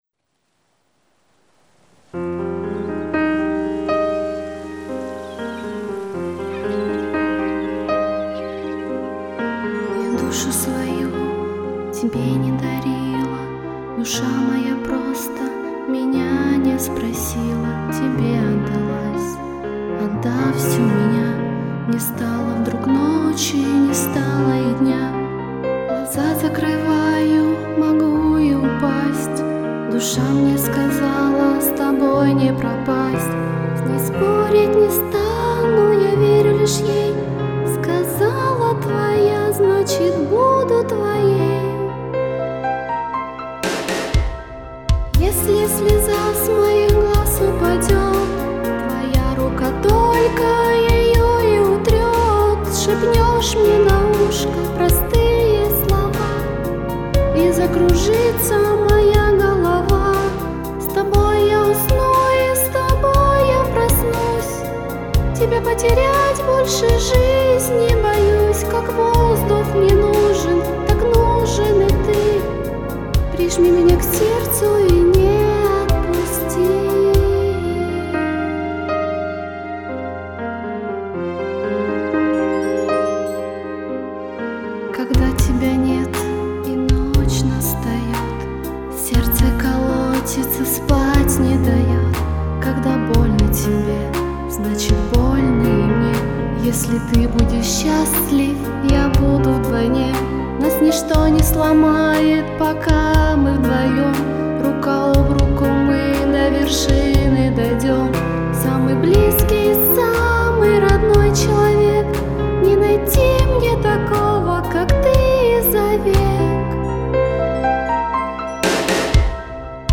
(для этой записи была специально сделана минусовка)